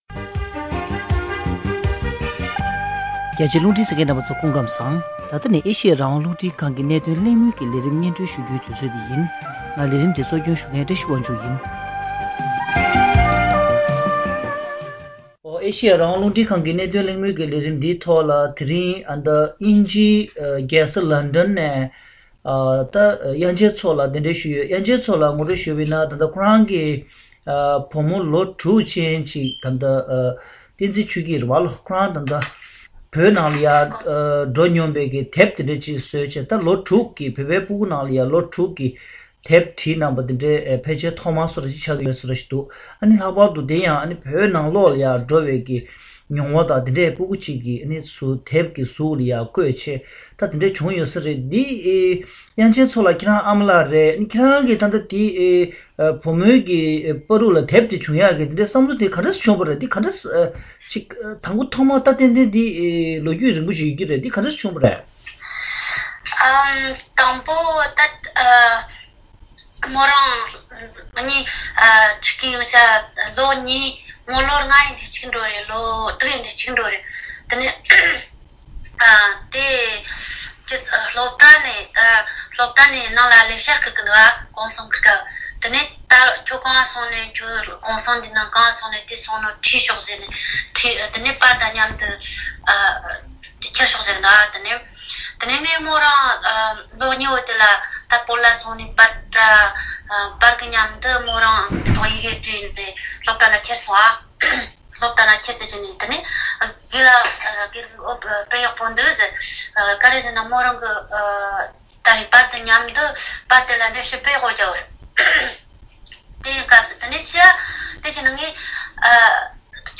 བོད་དང་བཙན་བྱོལ་ནང་གི་བྱིས་པ་གསོ་སྐྱོང་དང་སློབ་གསོ་སྤྲོད་ཕྱོགས་ཐད་གླེང་མོལ། — བོད་སྐད་སྡེ་ཚན།